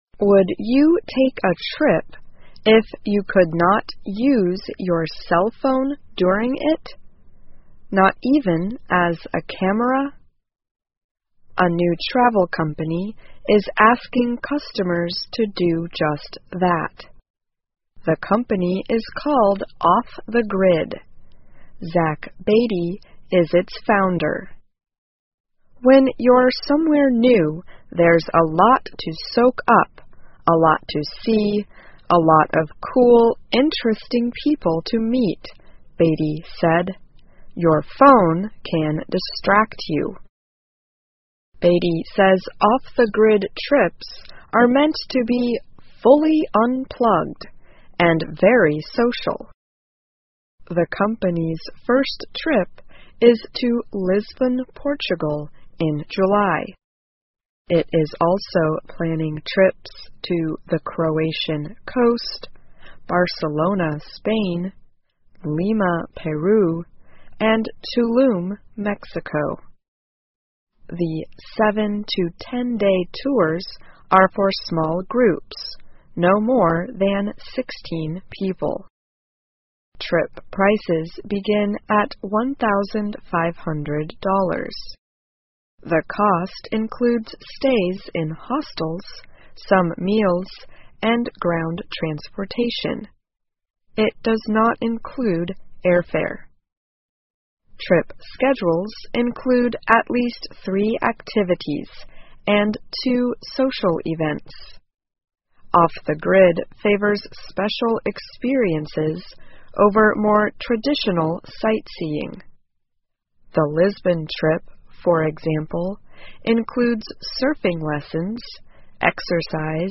在线英语听力室旅游公司禁止使用手机包括拍照的听力文件下载,2018年慢速英语(四)月-在线英语听力室